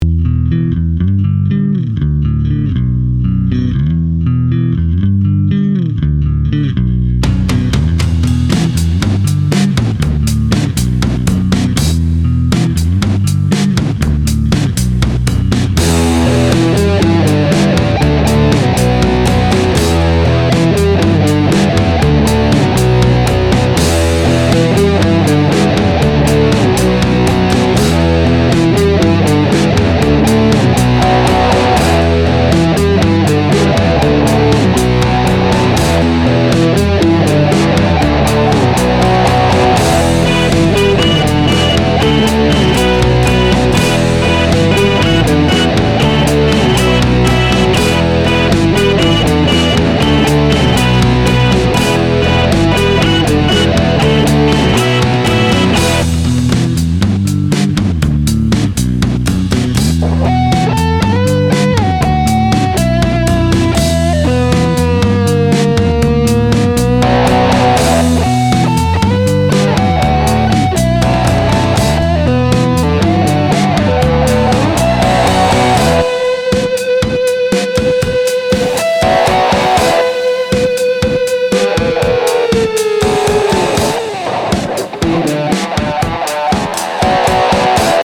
Here is a 1:30min riff out to test a) the origin effects BASSRIG to see if it fills a mix well, and cuts through a bit too. b) hear the recorded UAFX Lion with the bassrig to see if they sound better than my typical rock mix.
• BASSRIG
-placeholder drum beat
-Guitar slices from OCTATRACK, filtered etc
-rev2 synth ditty